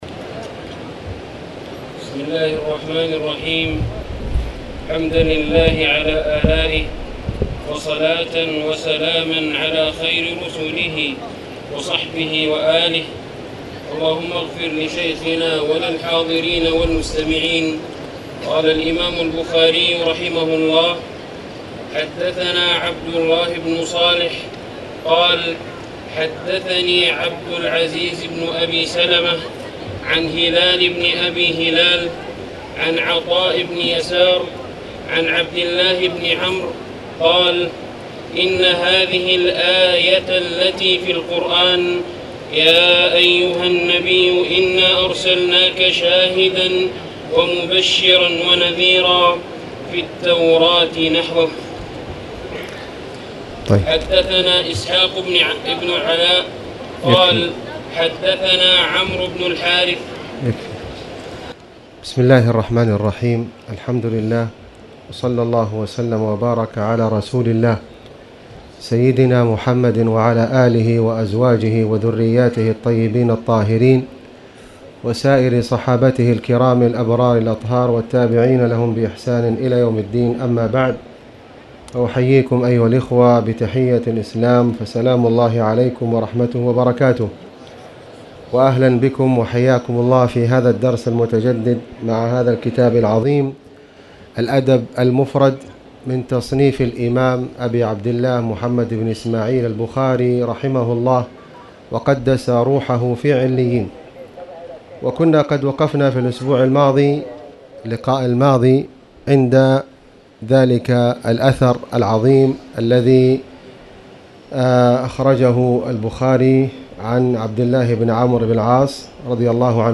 تاريخ النشر ١٣ ربيع الأول ١٤٣٨ هـ المكان: المسجد الحرام الشيخ: فضيلة الشيخ د. خالد بن علي الغامدي فضيلة الشيخ د. خالد بن علي الغامدي باب الإنبساط إلى الناس